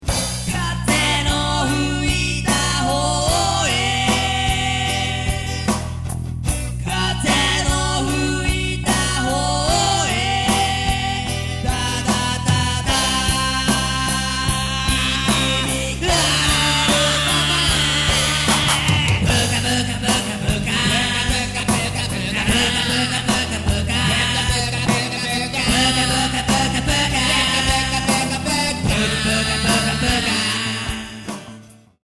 歌＆フォークギター
演歌＆フォーク